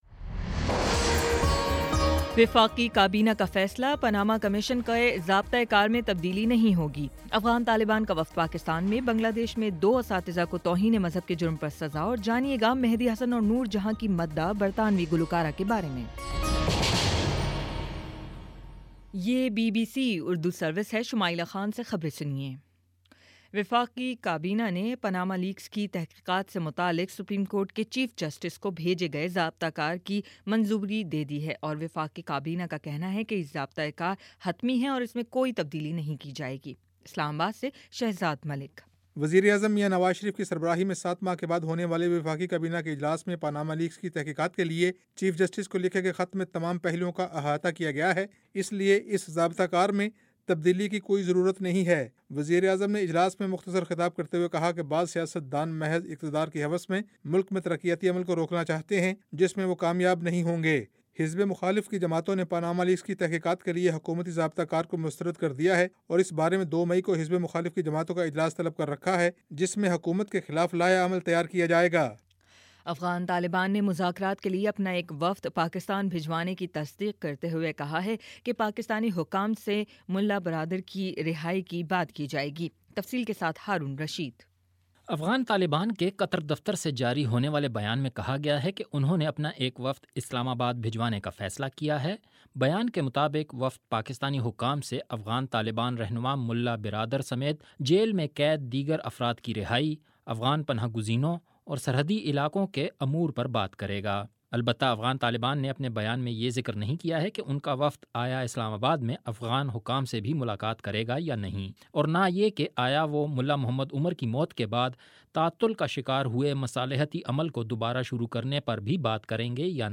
اپریل 27 : شام پانچ بجے کا نیوز بُلیٹن